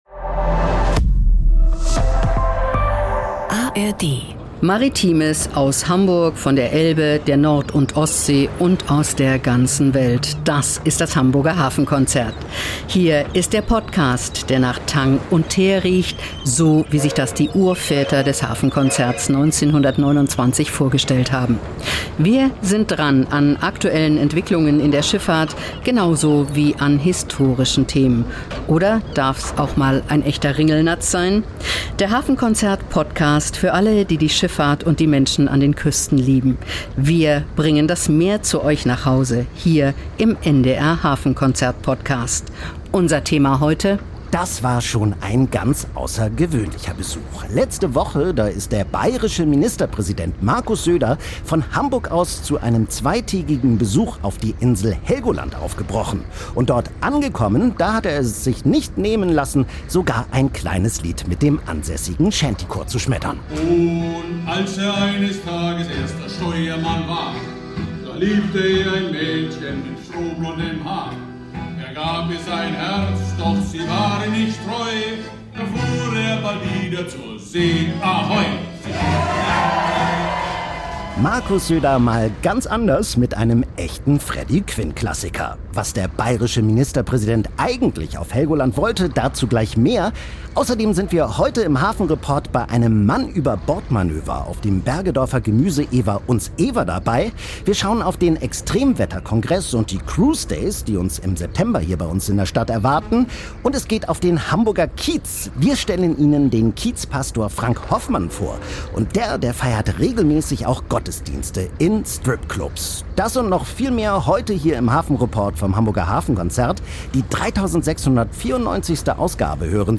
Die Themen im Einzelnen: Besuch auf Helgoland: Bayerns Ministerpräsident Markus Söder reist von Hamburg aus auf die Nordseeinsel, singt mit dem ansässigen Shantychor ein Lied – und äußert sich deutlich zum Länderfinanzausgleich.
Mann über Bord – auf Platt An Bord des Vierlander Gemüseewers „Uns Ewer“ spricht die Crew Plattdeutsch - wir waren auf dem Schiff zu Gast und haben ein "Mann über Bord"-Manöver begleitet.